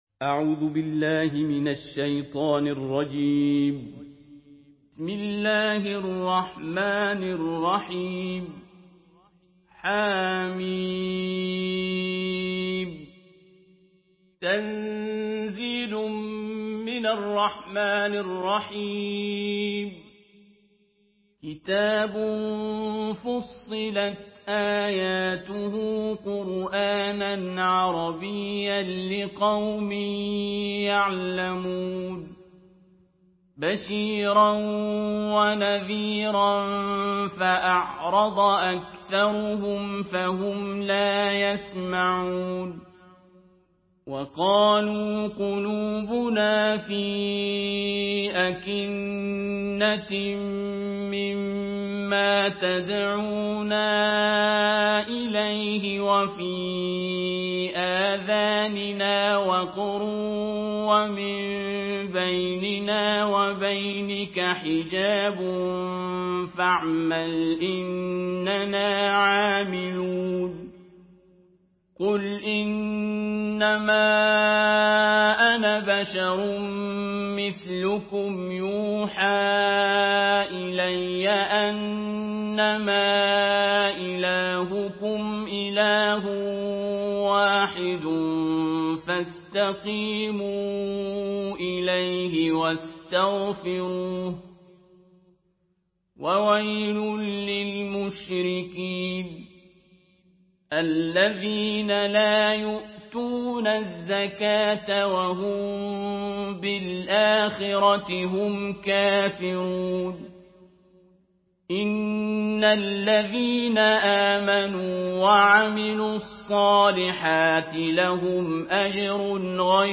سورة فصلت MP3 بصوت عبد الباسط عبد الصمد برواية حفص
مرتل